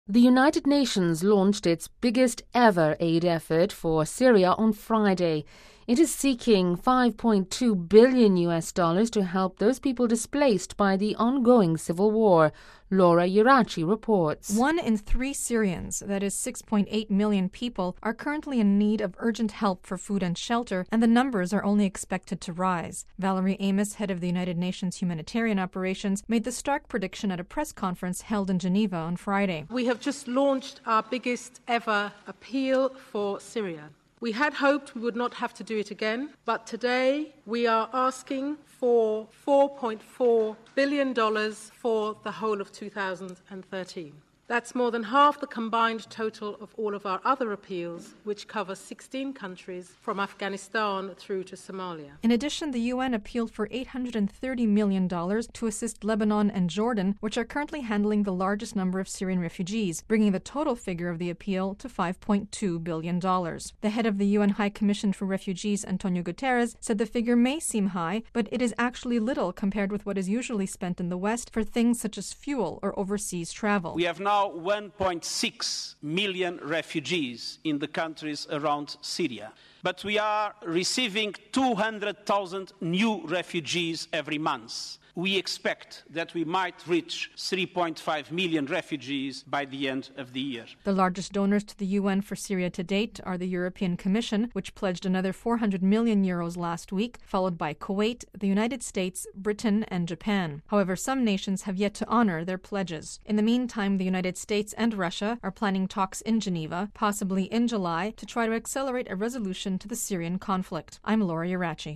Valerie Amos, head of the United Nations Humanitarian Operations, made the stark prediction at a press conference held in Geneva on Friday.